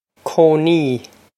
cónaí koh-nee
This is an approximate phonetic pronunciation of the phrase.